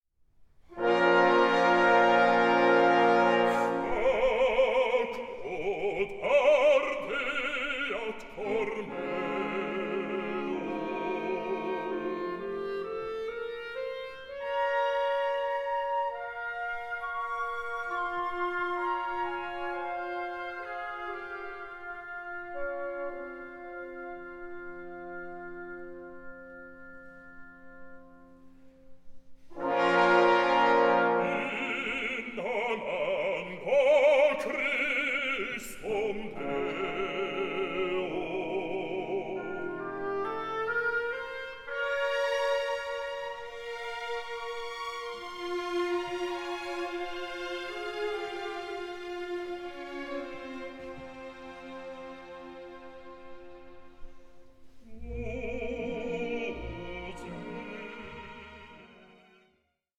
for soloists, choir and orchestra
Live recording